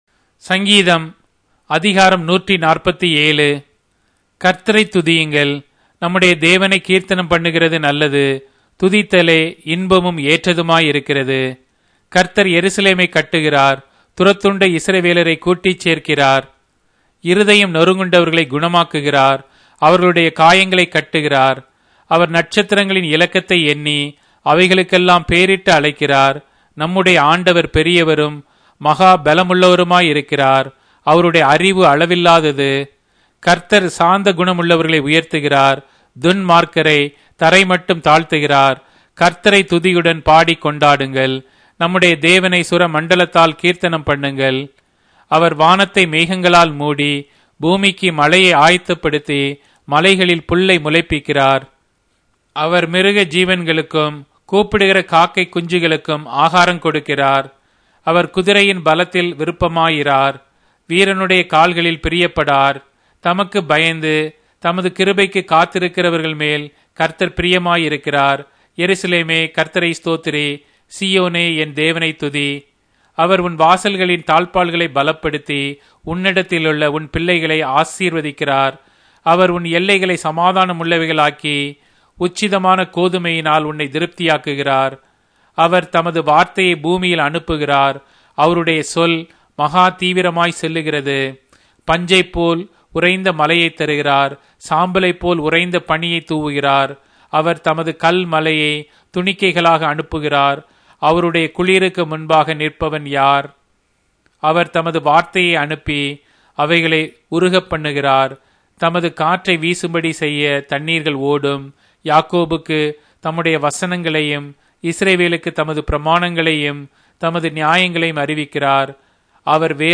Tamil Audio Bible - Psalms 135 in Orv bible version